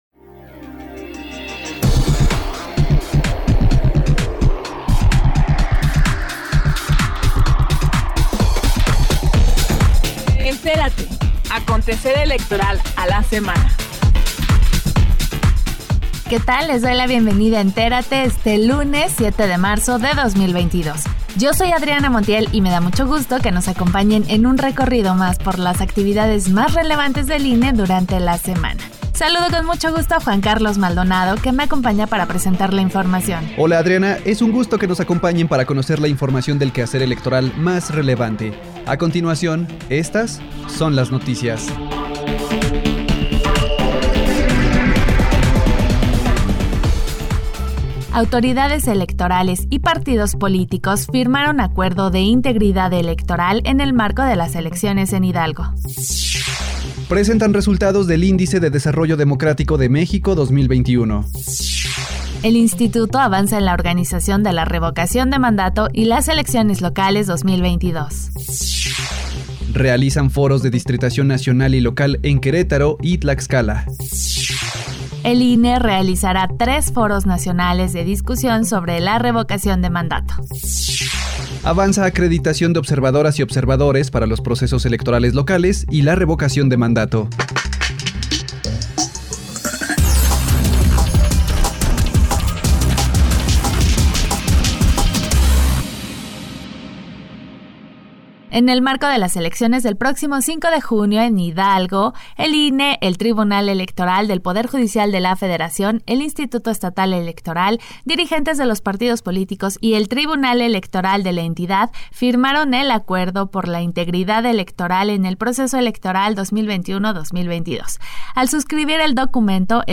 NOTICIARIO 07 MARZO 2022